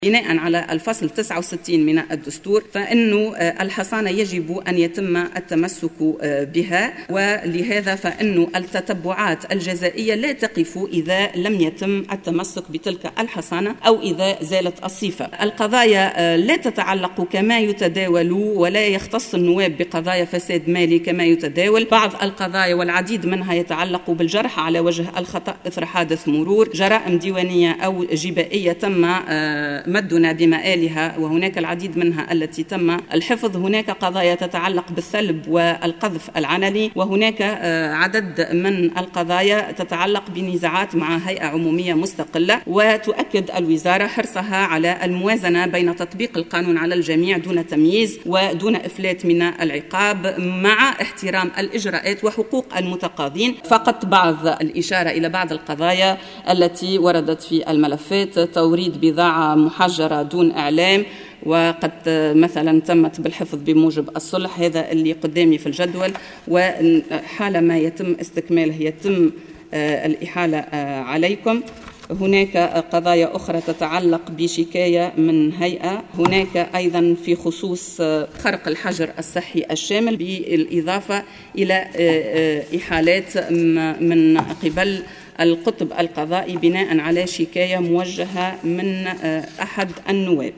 وأضافت بن سليمان خلال ردّها على اسئلة النواب في الجلسة العامة، أنّ الوزارة تراسل البرلمان عند التثبت من القضايا والنائب عند تمسكه بالحصانة كتابيا، لا ينتفي عندها حق المتقاضي، حسب تعبيرها.